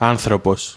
Ääntäminen
US : IPA : [ˈhju.mən]